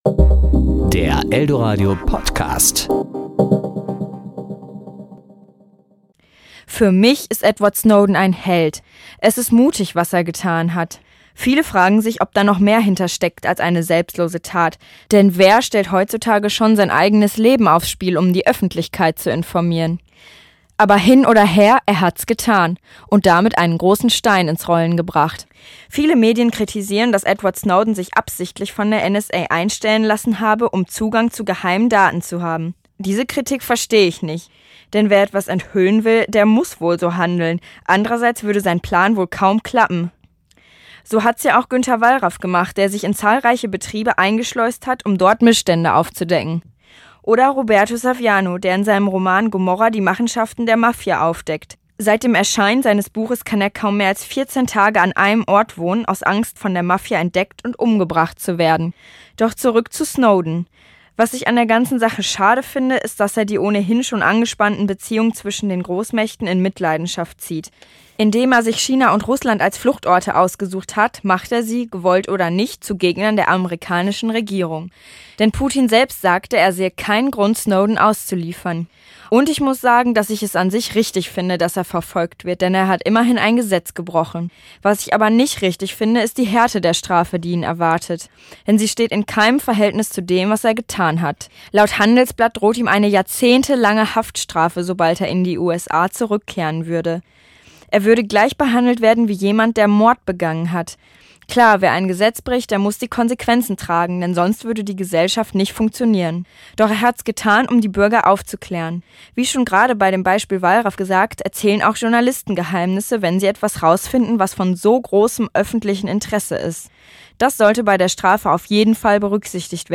Kommentar zu Edward Snowden